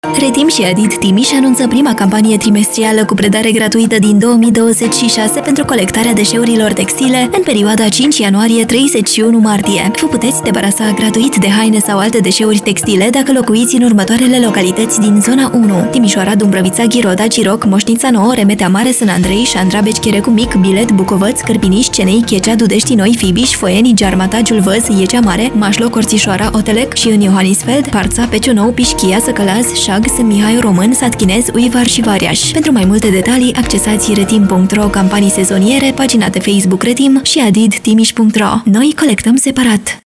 Spot-radio-Textile-trim.-I-Zona-1-Timisoara.mp3